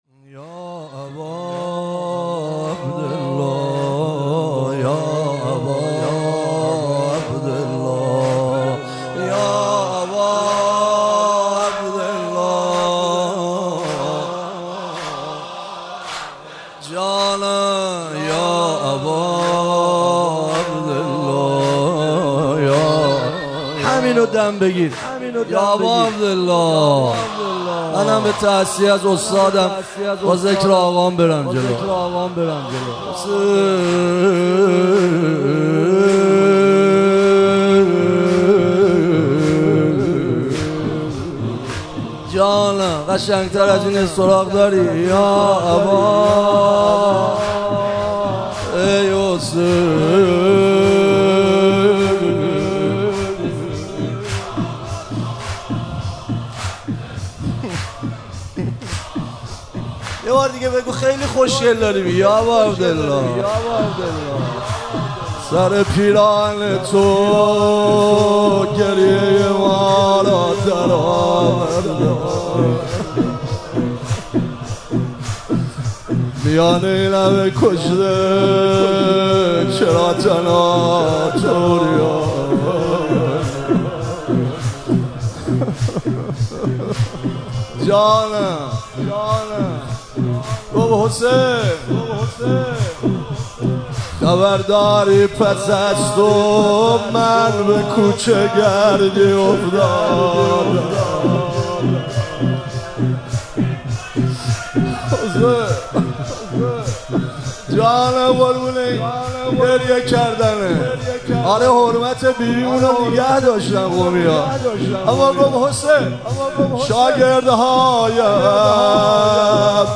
مراسم عزاداری وفات حضرت فاطمه معصومه (س)- آبان 1401